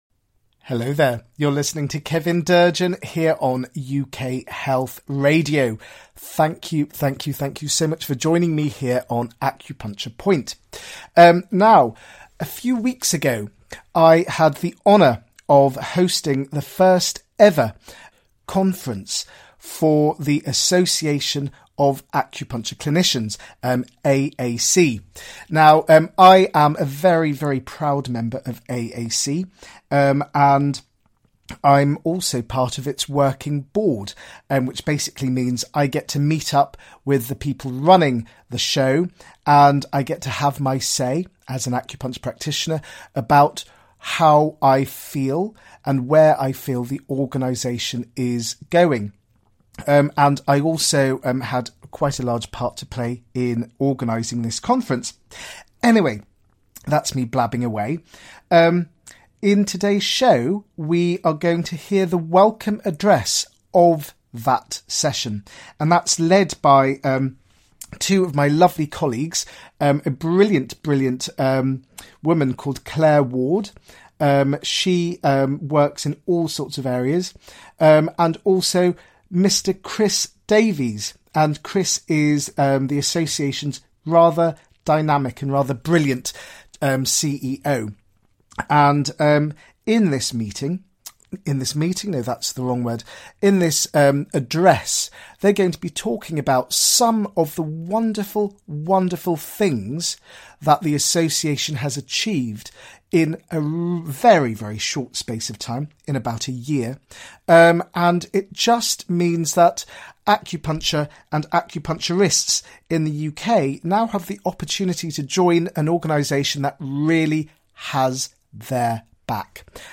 He will interview a wide range of people from practitioners to policy makers and will also provide health related updates particularly in the field of complementary health. As he is an acupuncturist there will be plenty of Chinese medicine related content. He will also play some gorgeous music to uplift your soul and get your feet tapping with happiness.